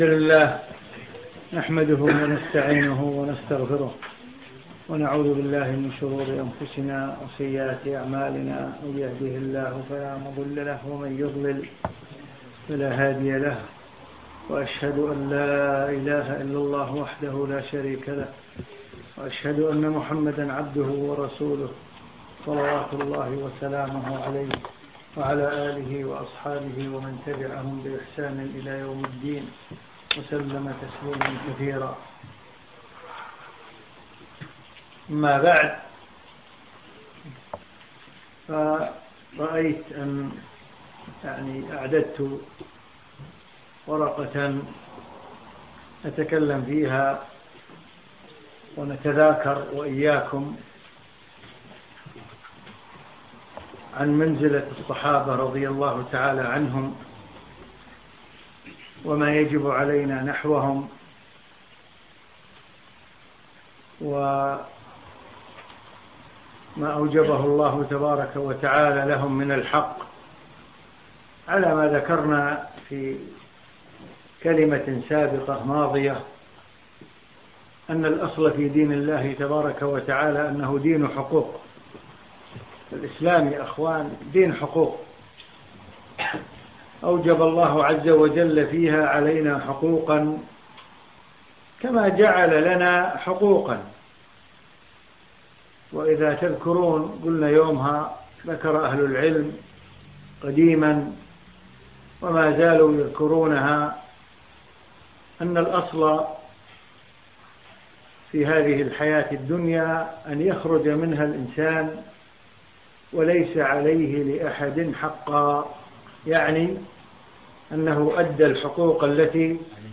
في مسجد الصحابة السلفي في مدينة برمنجهام البريطانية في 19 شوال 1435